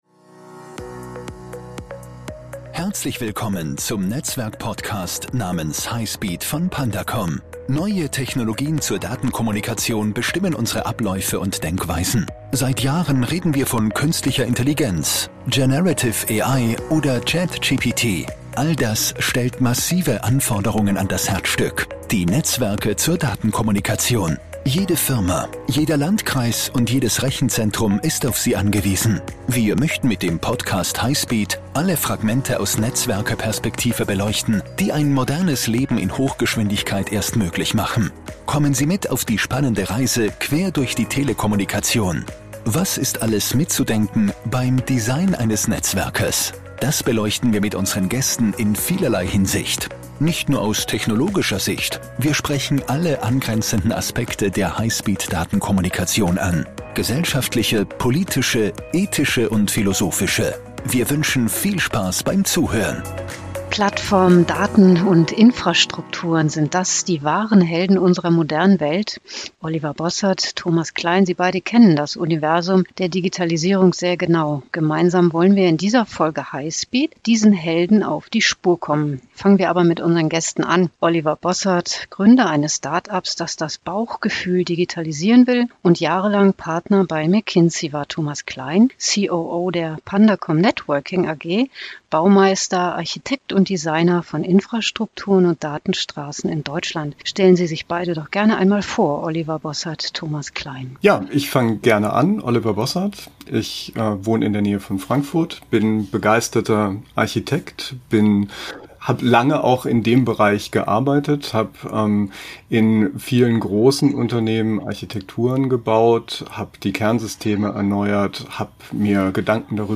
Zwei Experten im inspirierenden Austausch.